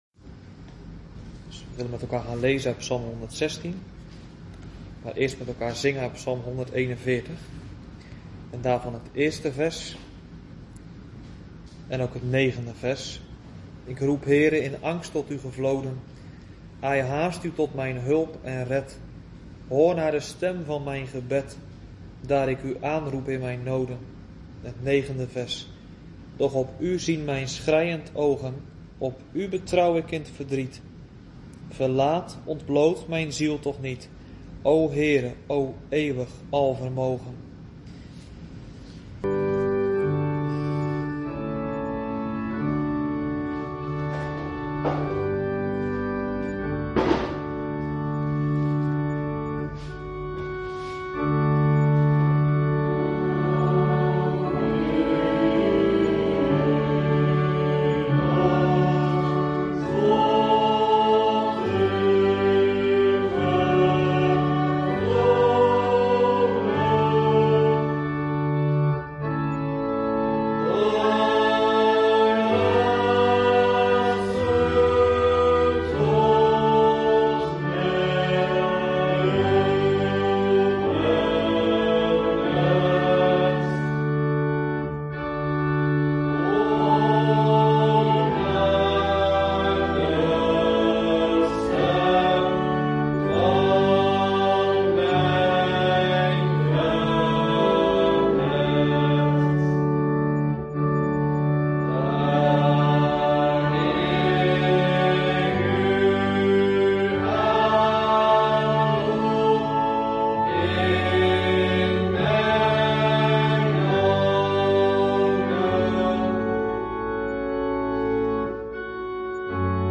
LEZING 34 - DORDTSE LEERREGELS - H5 - HET GEBED ALS MIDDEL TOT VOLHARDING - DEEL 1 - DE AANSPRAAK EN AANBIDDING VAN GOD